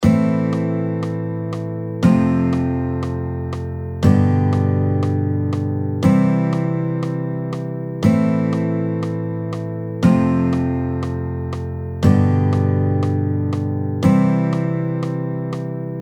それではkey in Cで上記のコード進行を記しますとAm7-F-G-Cとなります。
Ⅵm-Ⅳ-Ⅴ-Ⅰ音源